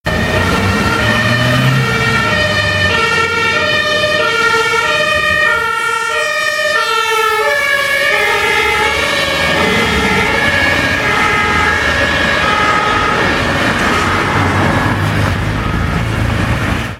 Das Geheule wird immer mehr …